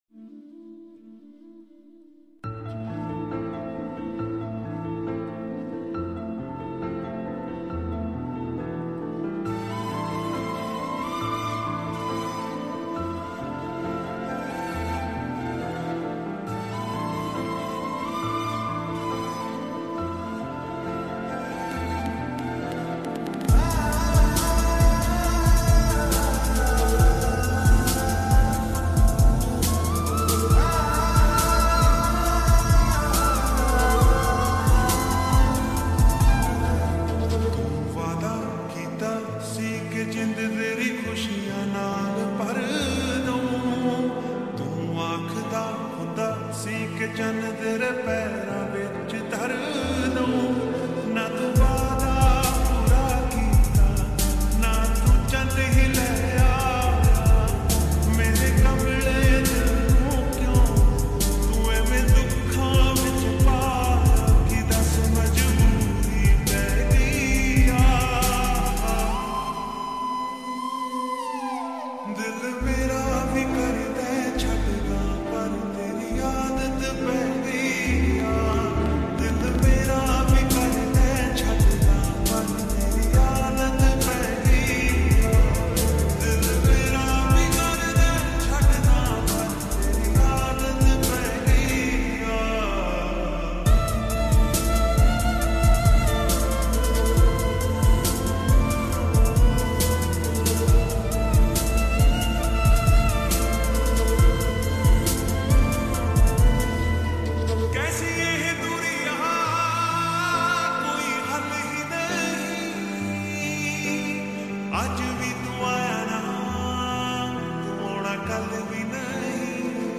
Full Song Slowed And Reverb
old Sad Song